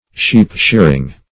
Sheep-shearing \Sheep"-shear`ing\, n.